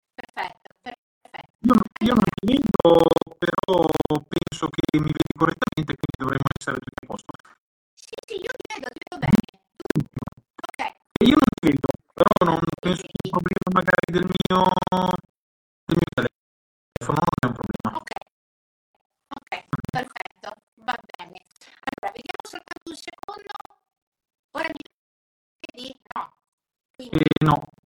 Scarica il podcast dell'evento